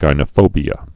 (gīnə-fōbē-ə, jĭnə-)